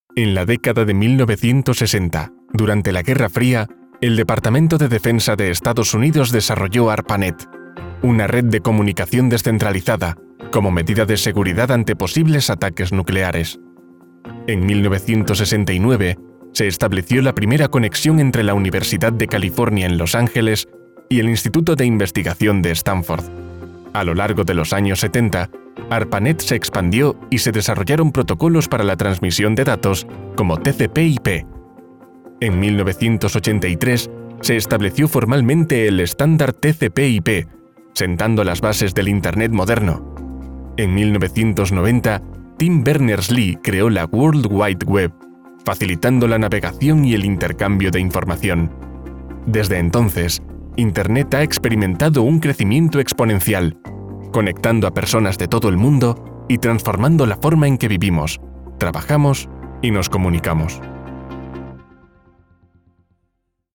Género: Masculino
ELearning